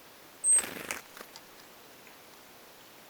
mikä tiaislaji?
Äänite: tämä yhtäkkinen innostunut ääntely
tulee siitä, että juuri saapui ruokinnalle
uusi tiaisparvi
Ja siinä oli esimerkiksi tuo töyhtötiainen mukana.